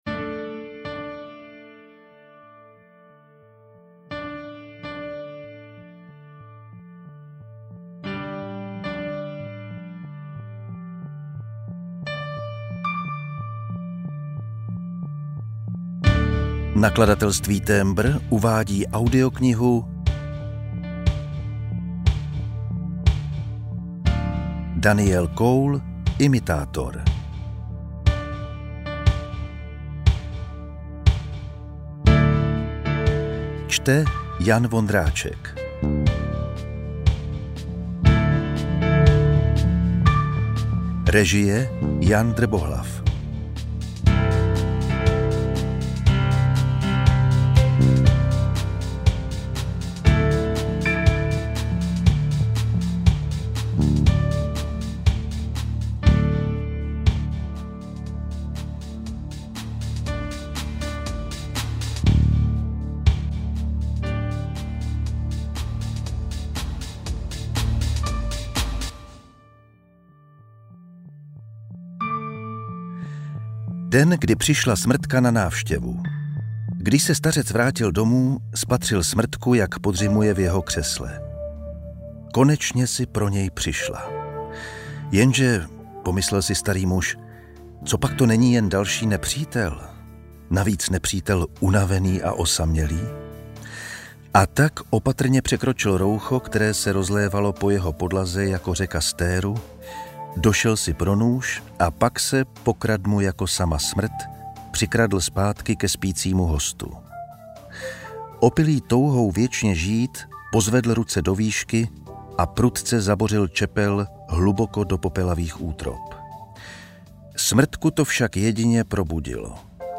audiokniha_imitator_ukazka.mp3